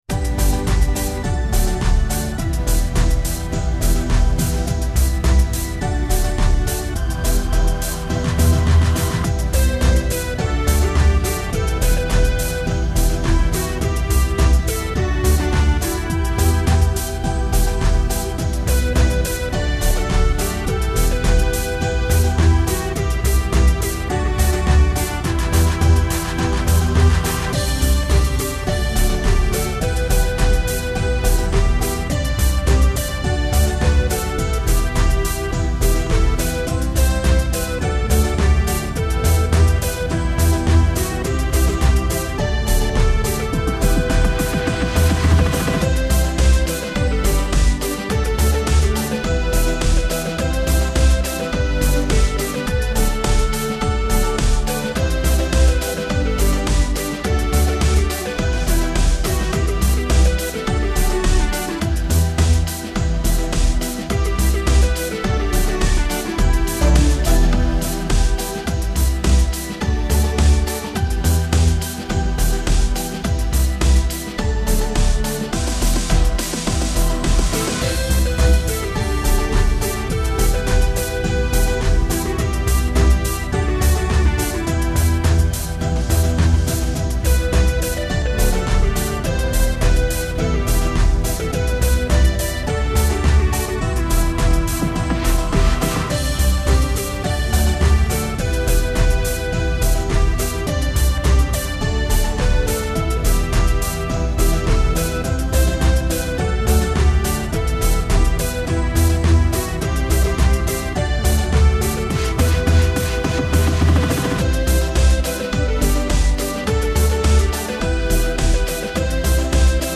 pop song
I had a lot of fun making a backing for it.